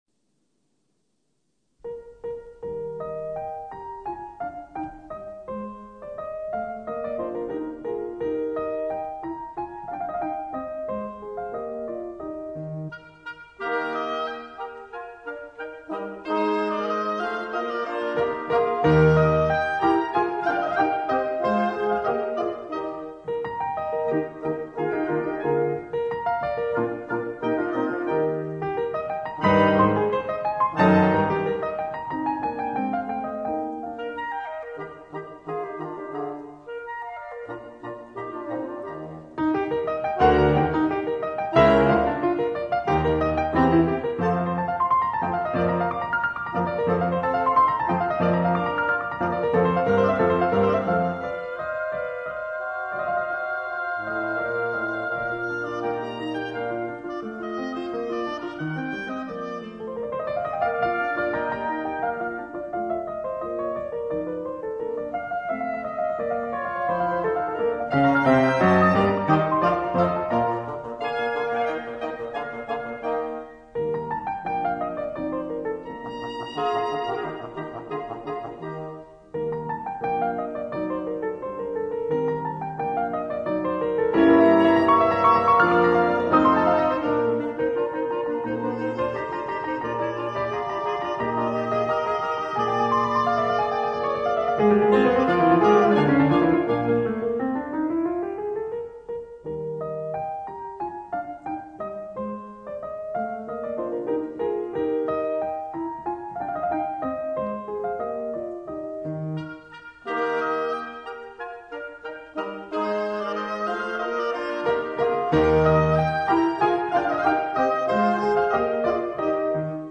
钢琴与铜管五重奏
音樂類型：古典音樂